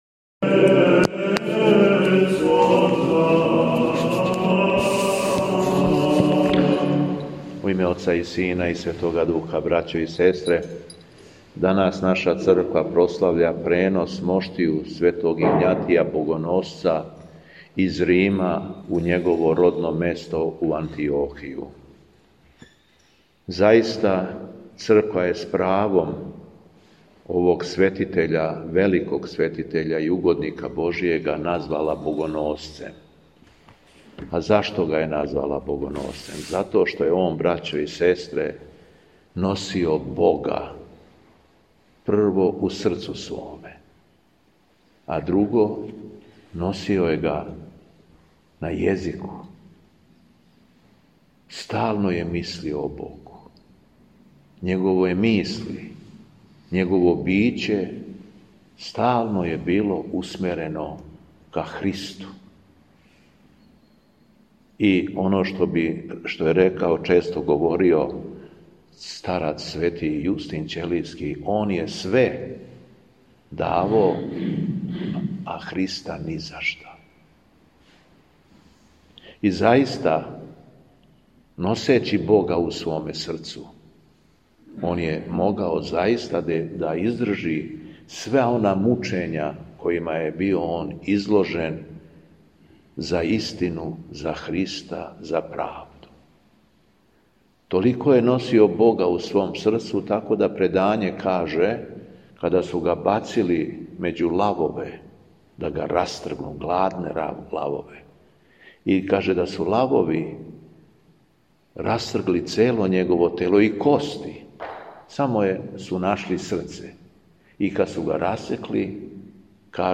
СВЕТА ЕВХАРИСТИЈА У БРЕСНИЦИ
Беседа Његовог Високопреосвештенства Митрополита шумадијског г. Јована
Дана 11. фебруара лета Господњег 2026. на празник пренос моштију Светог Игнатија Богоносца, Његово високопреосвештенство Митрполит шумадијски Господин Јован началствовао је свештеним евхаристијским сабрањем у крагујевачком насељу Бресница у храму Светог Јоаникија Девичког.